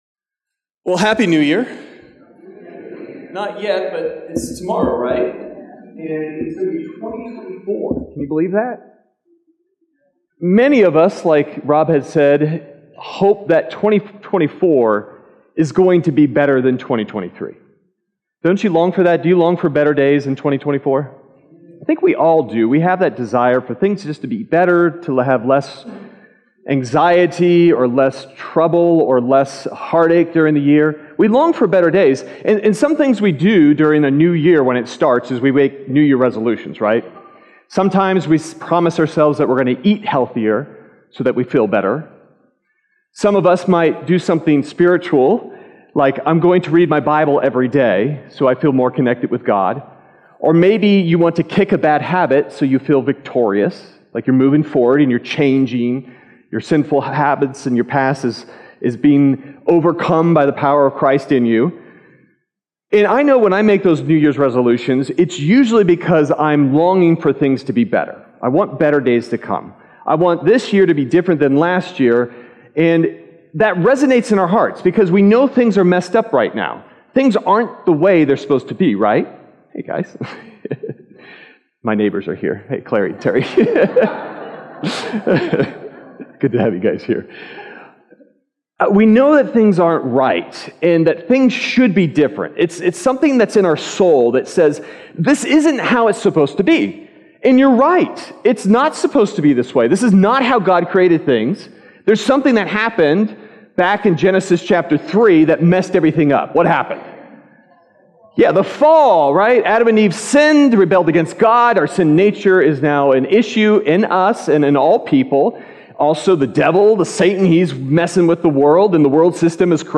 Sermon Detail
December_31st_Sermon_Audio.mp3